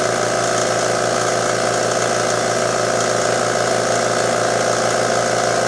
COMPRESSOR.WAV